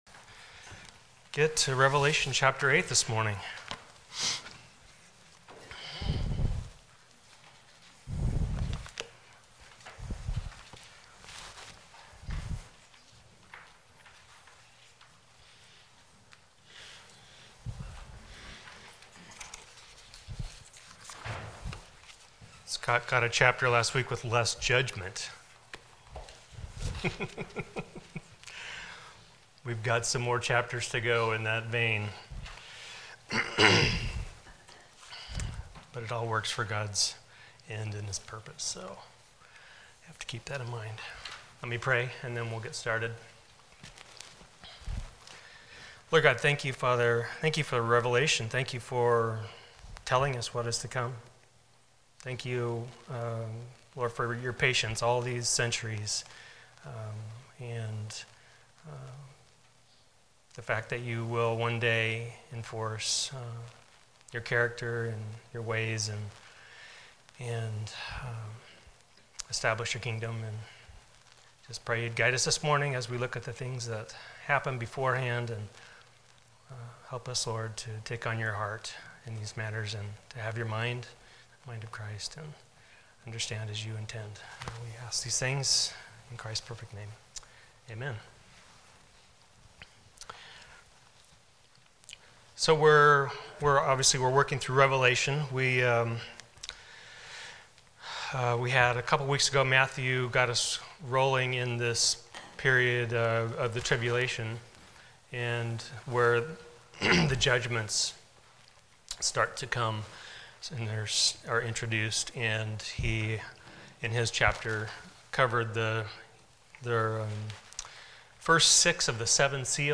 Passage: Revelation 8 Service Type: Sunday School